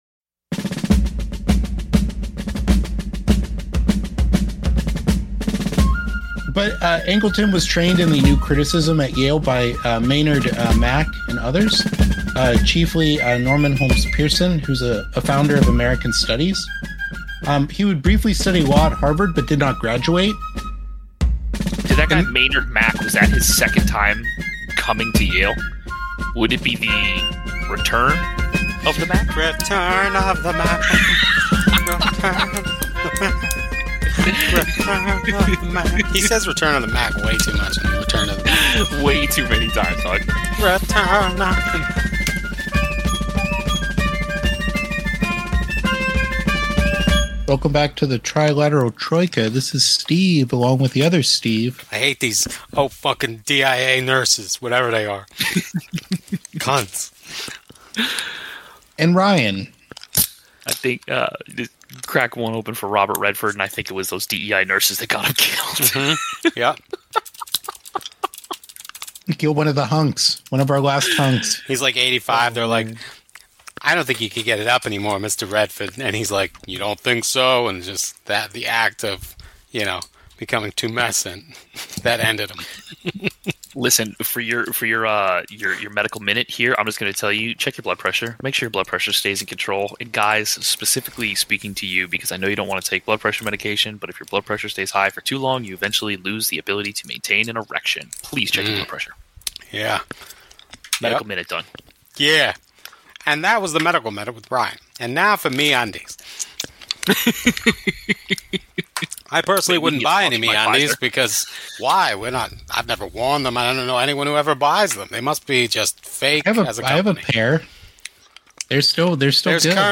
Disclaimer: We are three inebriated dudes(mostly) talking about history(mostly).